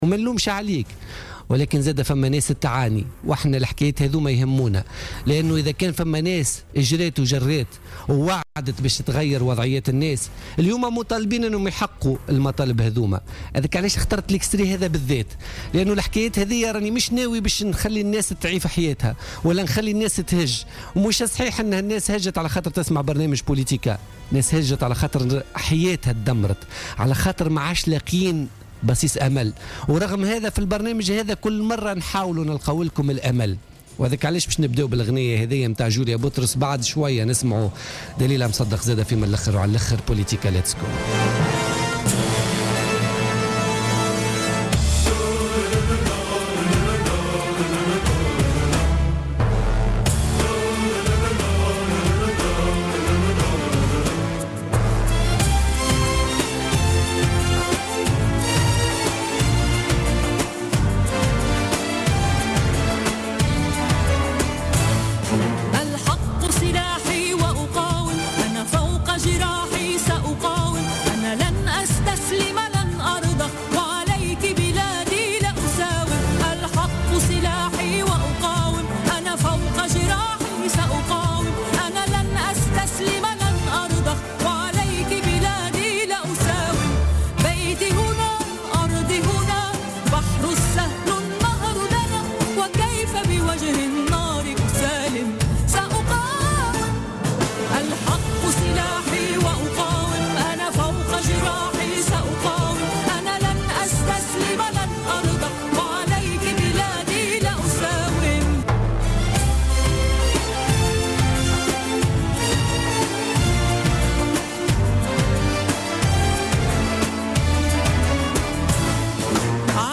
أكدت كاتبة الدولة المكلفة بملف شهداء وجرحى الثورة ماجدولين الشارني في مداخلة...